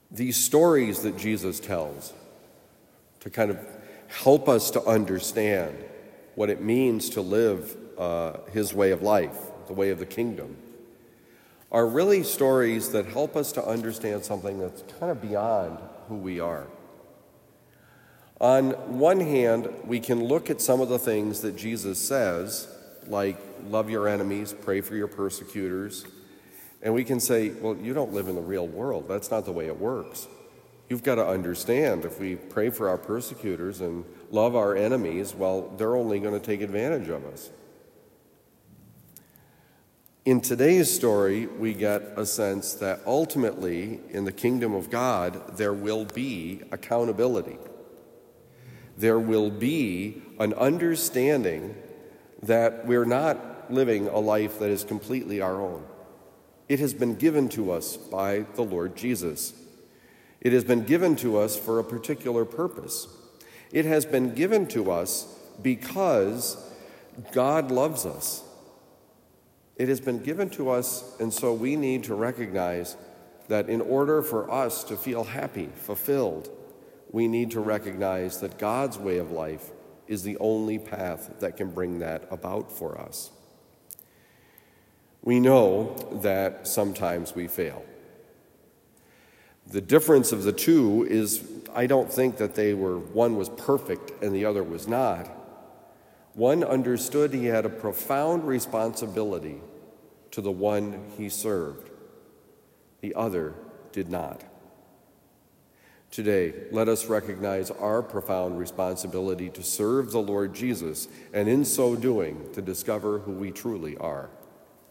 Homily given at Christian Brothers College High School, Town and Country, Missouri.